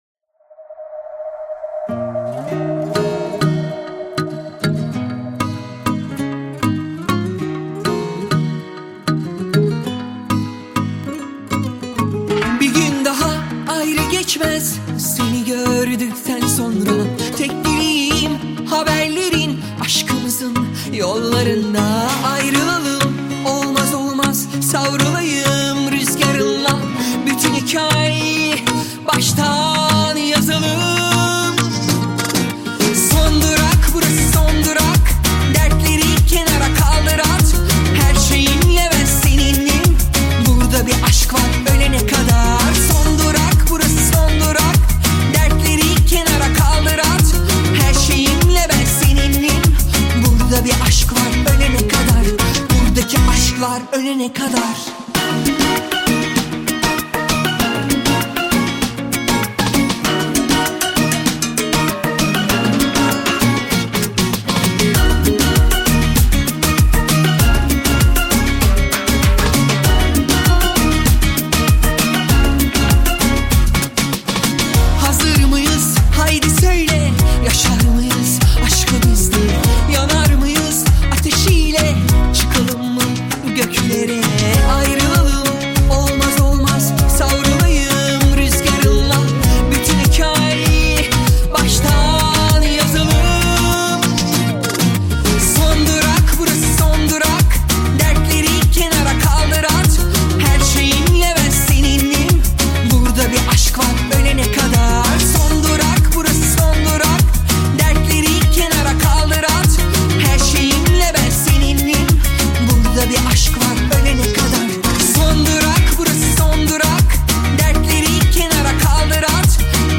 • Категория: Турецкая музыка